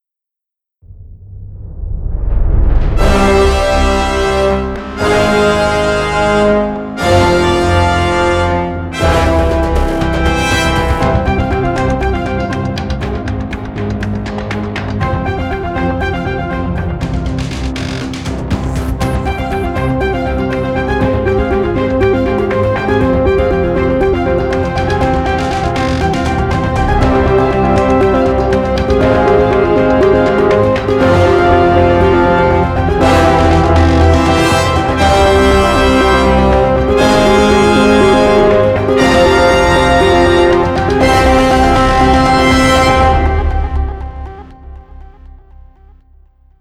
全面的铜管乐。
一个图书馆，最终捕捉到黄铜家族的广泛表现范围和多样性。
MSB由真正的铜管大师演奏，一次录制一名演奏者。
同步渐强和颤音
踏板音提供扩展的低音范围